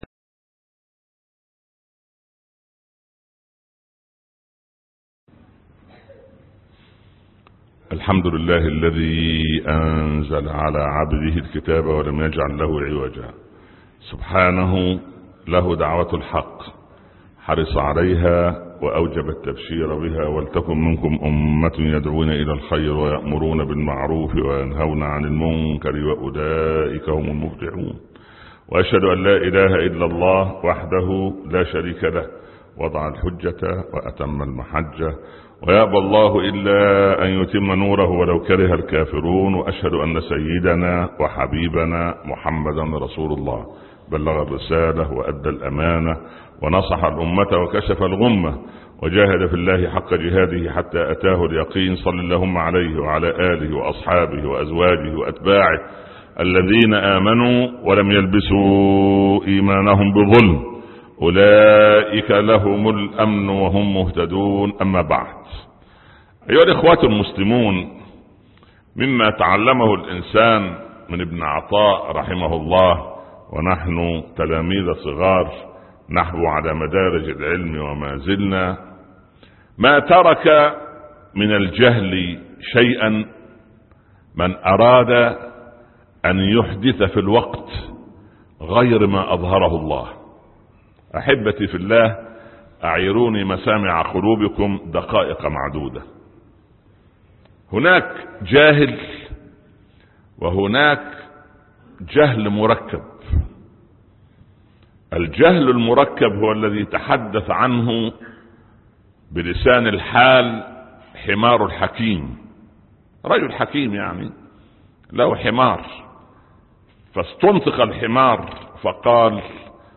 الأحداث السبعة (19/8/2016 ) خطب الجمعة - الشيخ عمر بن عبدالكافي